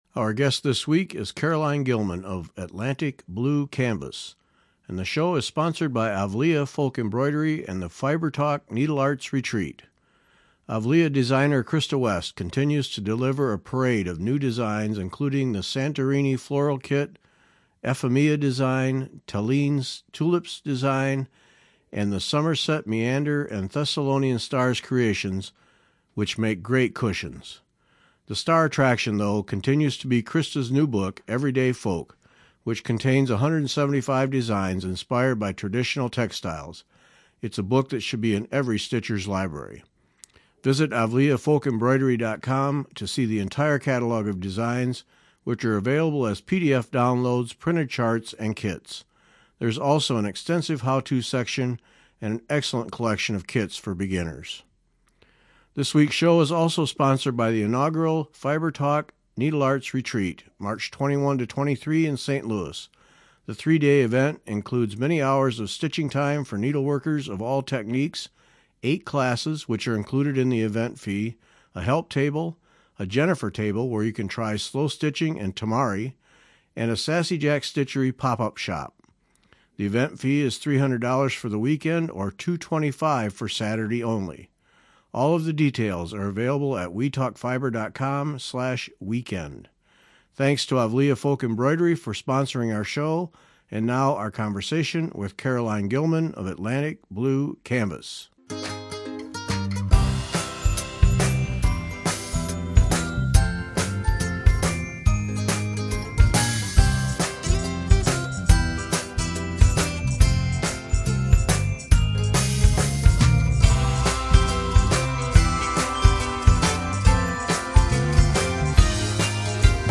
Today she represents 11 artists and provides a wide-ranging catalog of designs. Learn all the details in this week’s conversation.